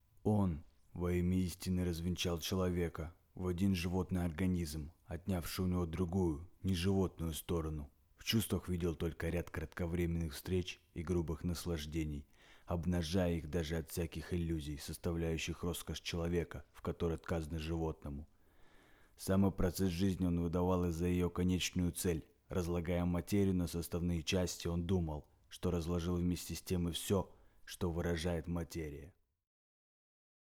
Вариация №1 (просто чтение)